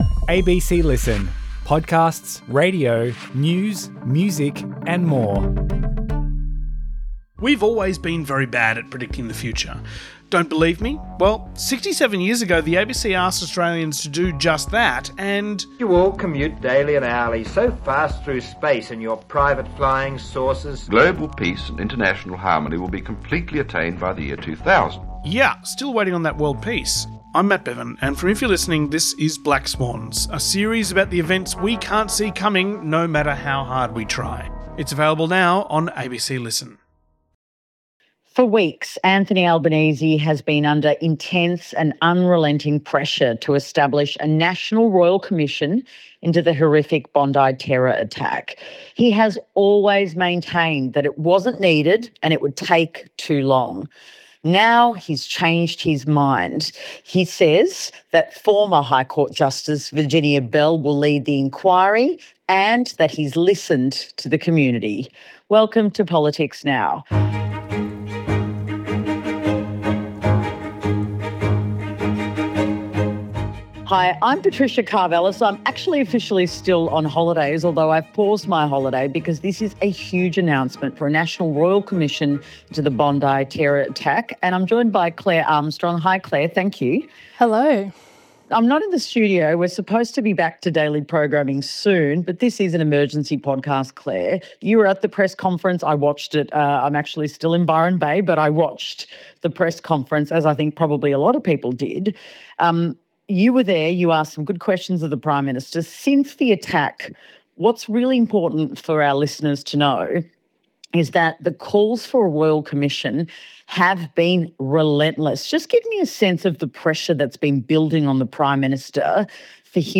From Monday to Wednesday, Patricia Karvelas will chat to a rotating cast of the ABC's biggest political journalists about the latest news.